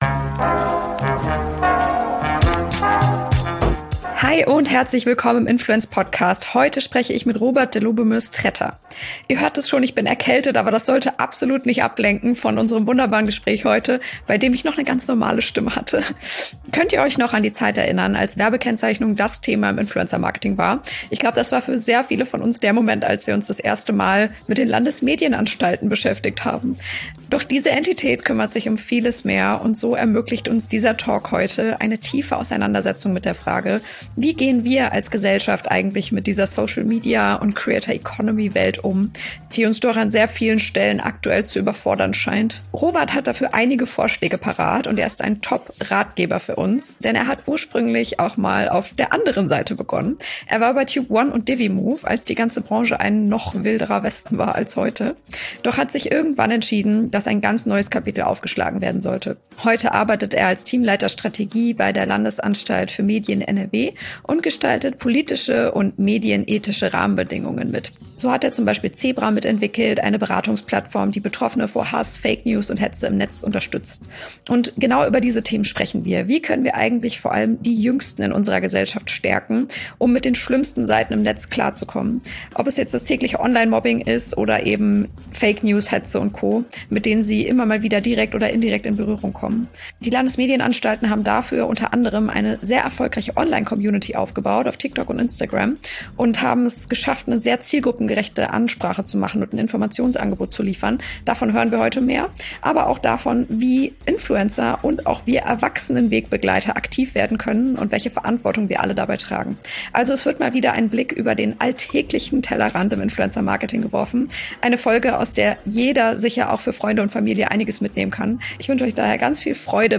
In meinem Podcast tauchen wir tief in die Welt der Creator Economy ein und gehen der entscheidenden Frage auf den Grund: Wie können Marken und Organisationen Influencer Marketing erfolgreich einsetzen, statt nur dem Trend hinterherzulaufen? In jeder Folge spreche ich mit spannenden Gästen aus der Branche – von Marketing-Profis über Top-Influencern bis hin zu Brand-Strategen.